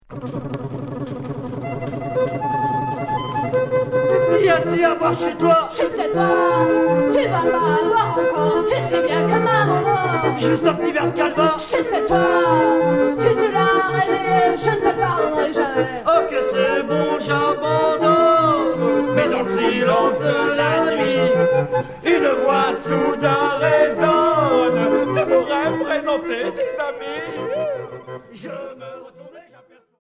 Folk Rock alternatif